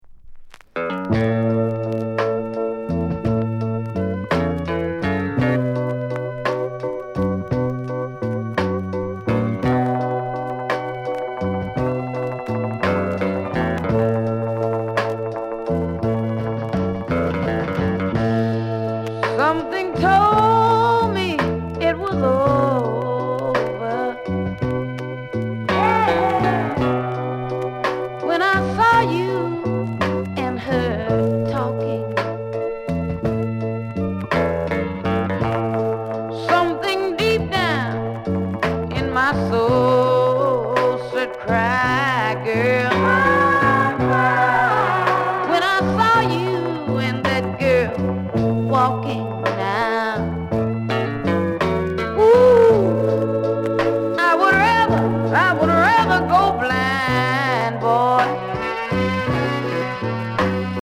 Sound Condition VG(OK)